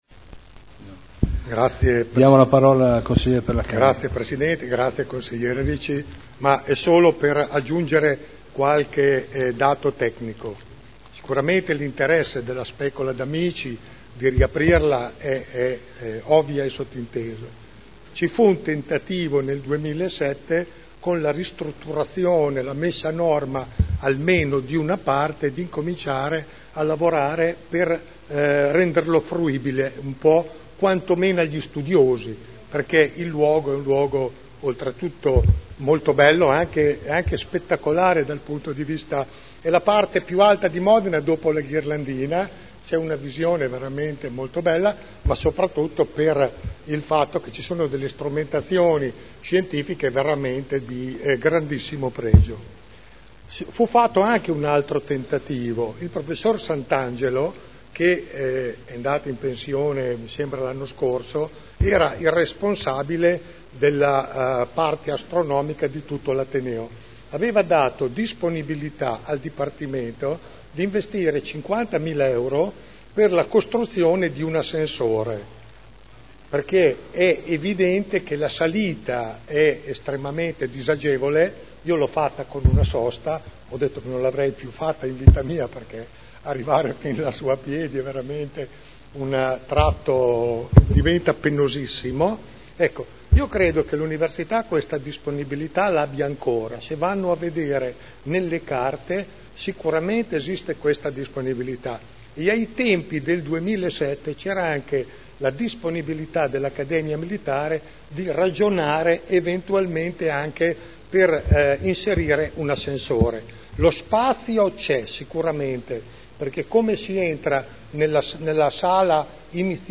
Federico Ricci — Sito Audio Consiglio Comunale